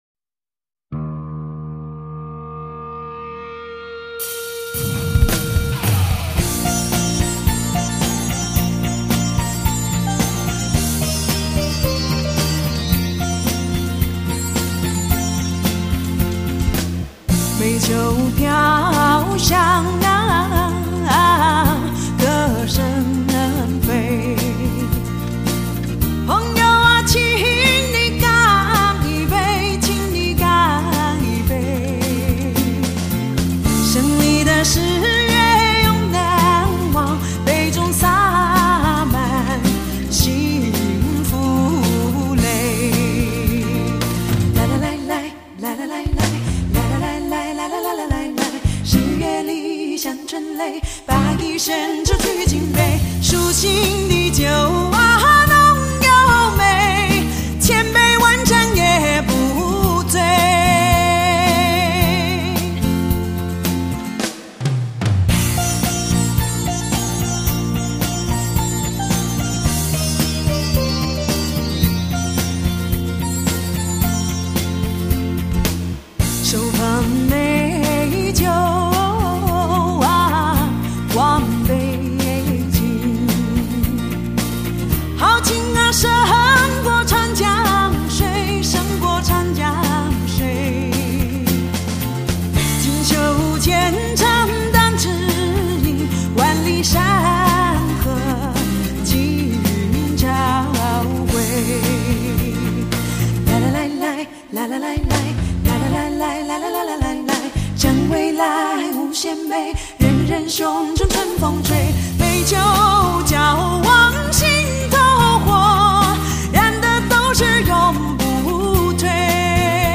也许只能透过她那自然贴心，还带豪迈的歌声，才可以唤醒我们对流逝岁月的记忆和赞美。